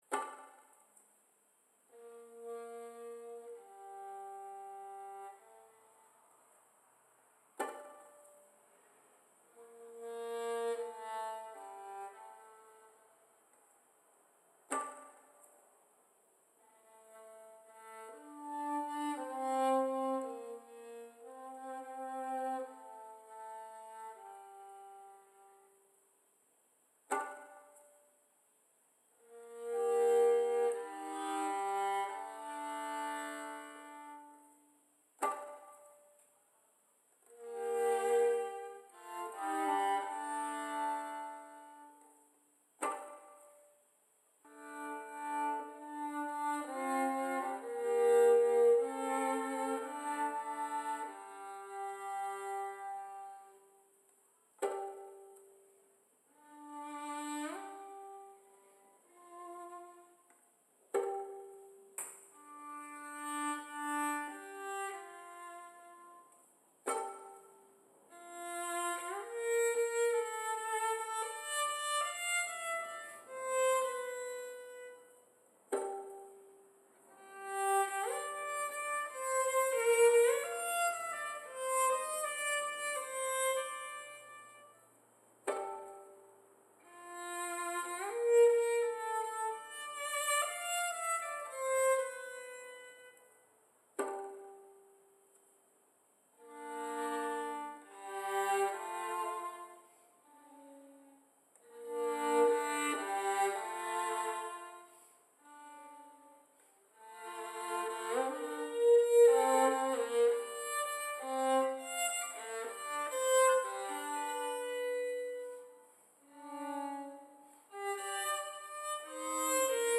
It’s a tentative walking piece, a pilgrimage in the wrong direction, from Merstham to Farnham, reflecting the cross section of the walk, chalk, sand, mud, two rivers.